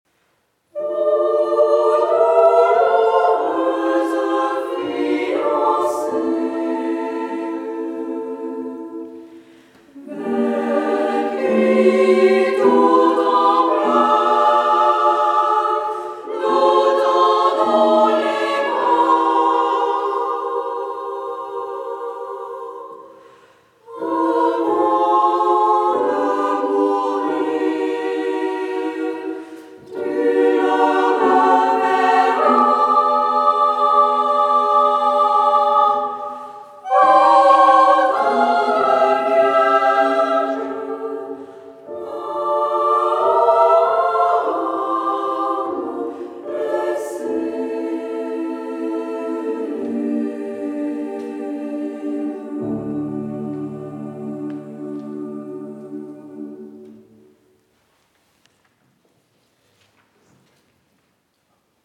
2002/Per Cantare/CD de concert n°4/Choeur de femmes: